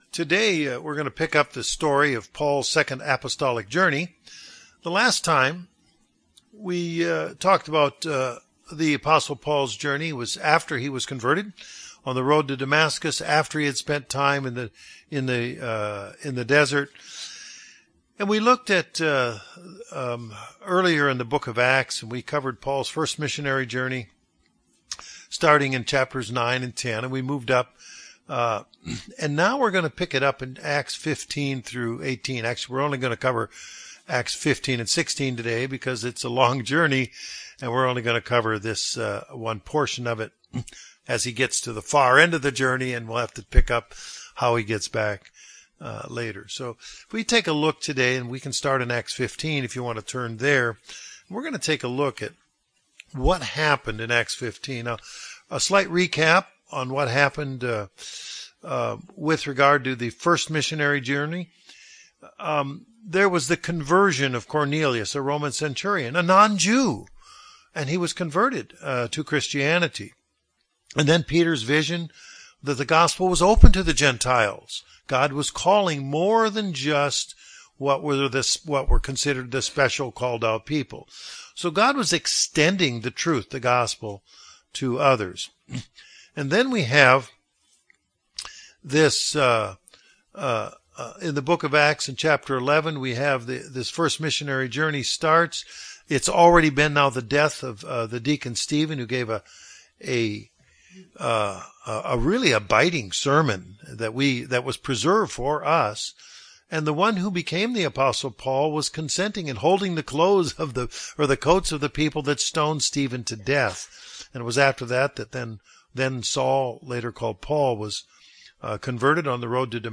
This Bible Study walks us through the second missionary journey of the Apostle Paul, beginning in the book of Acts, chapters 15 & 16.